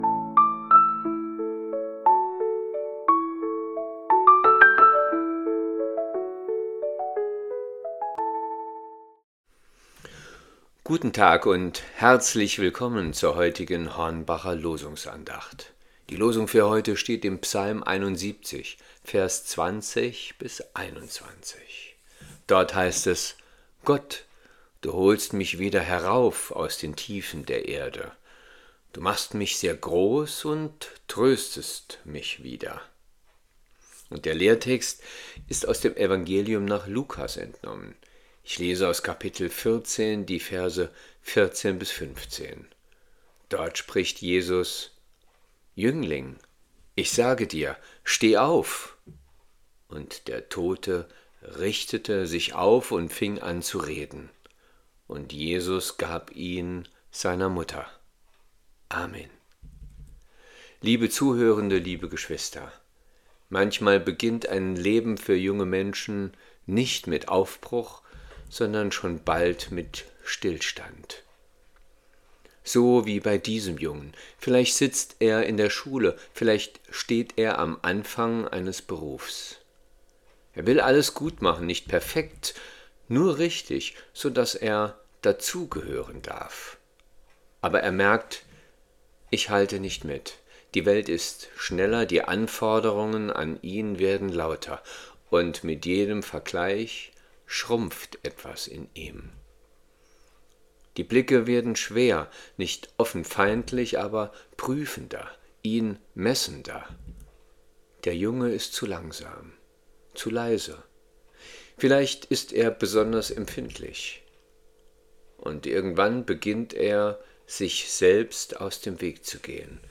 Losungsandachten